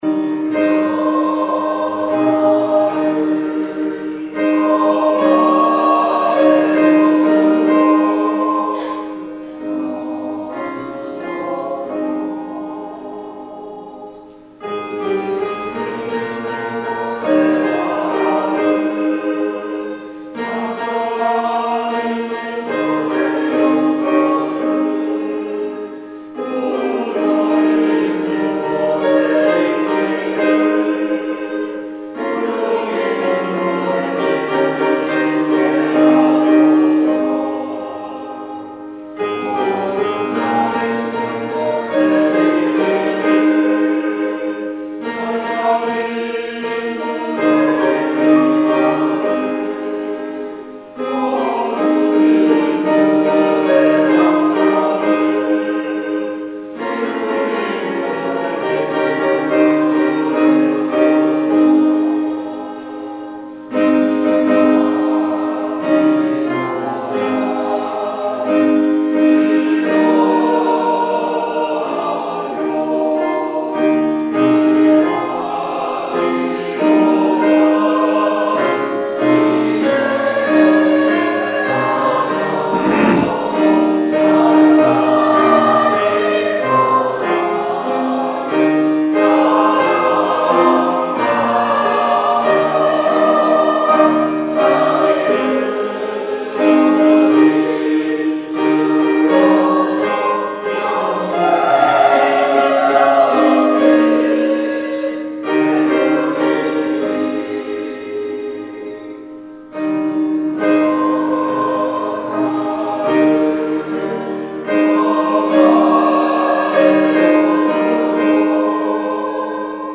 2015년 5월 10일 호산나 찬양대: 주여 나를 평화의 도구로
성가대 찬양